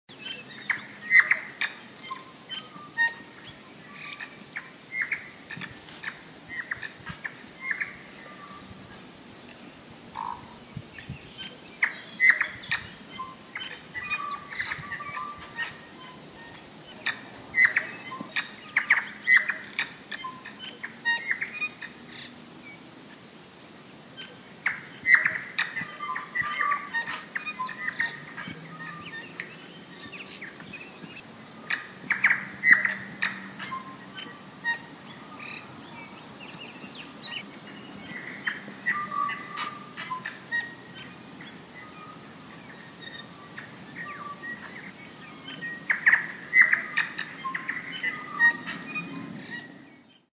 NZ early birds
Vroeg in de ochtend nam ik deze wonderbaarlijke klanken op. De opname is een beetje geknipt en geschoren, zodat het een soort liedje word. Als iemand weet welke vogel(s) te horen zijn dan hoor ik dat graag.
early-NZ-birds.mp3